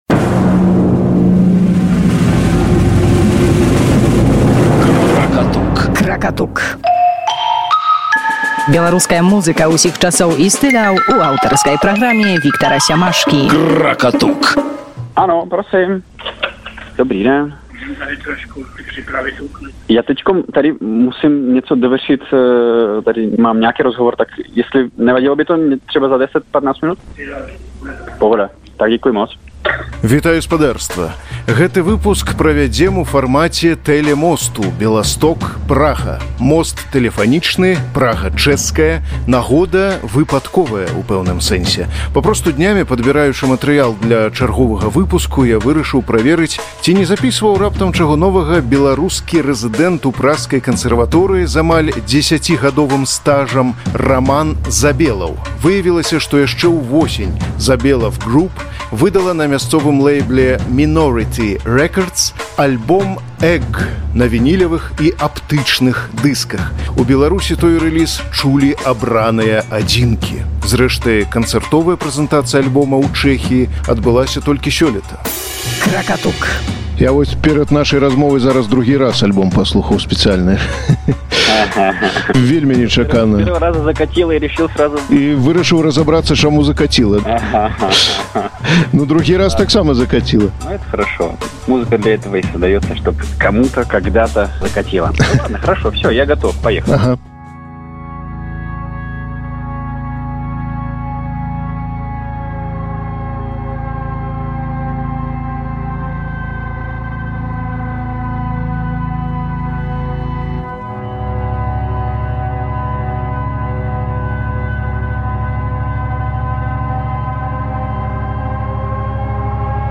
“Кракатук” – радыёпраграма пра беларускую музыку ў самым шырокім сэнсе гэтага азначэння: творы розных эпохаў і стыляў (фальклор і электроніка, рок і джаз, класіка і авангард). Кожны выпуск уяўляе сабой змястоўнае даследаванне на зададзеную тэму.
Дэманстрацыя матэрыялу, як правіла, суправаджаецца каментарамі аўтараў і знаўцаў.